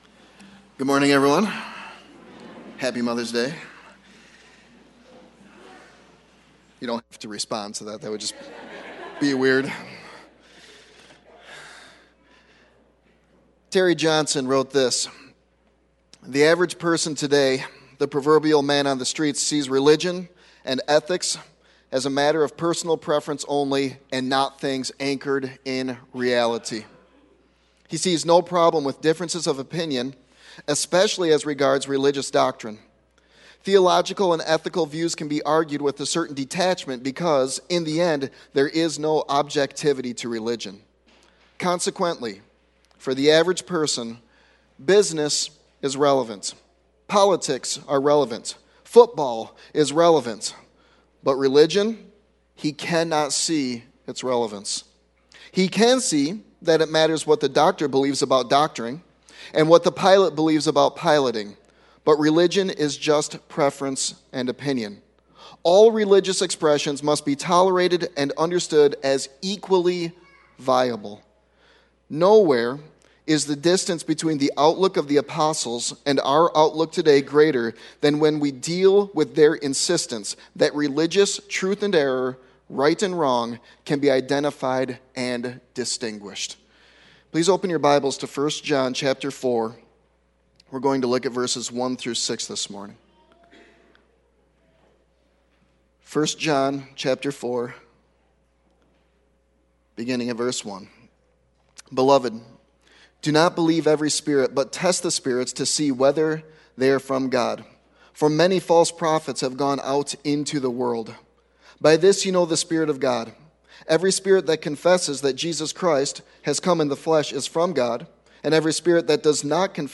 Sermon Text